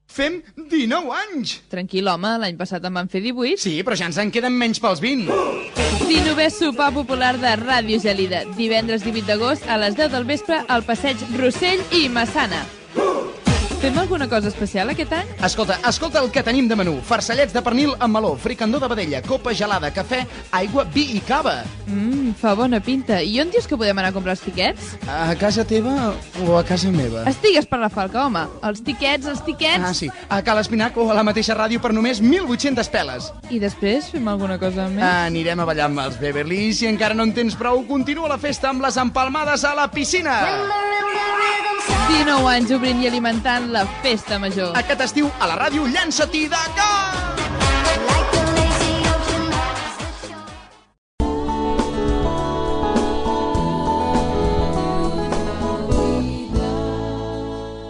Promoció del XIX Sopar Popular de Ràdio Gelida, indicatiu de l'emissora Gènere radiofònic Publicitat